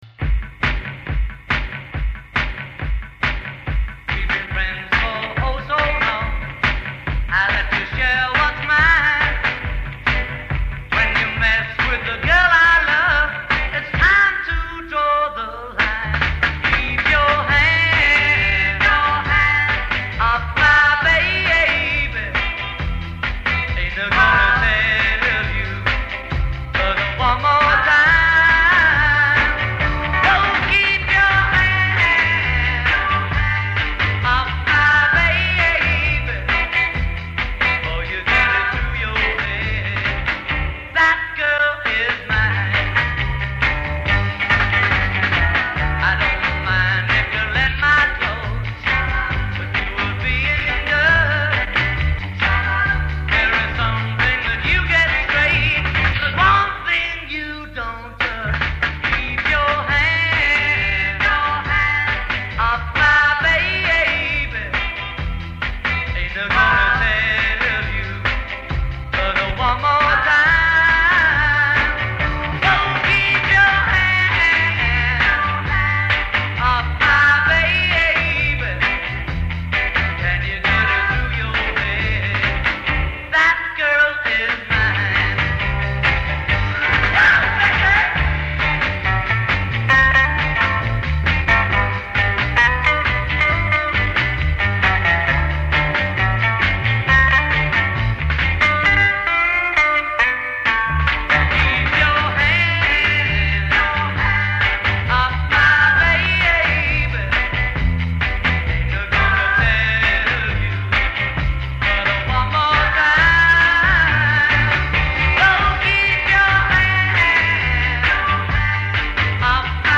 Intro 0:00 4 drums and vocal (other materials inaudible)
B Chorus : 32 soloist with responding chorus b
A Verse : 16 guitar version of vocal line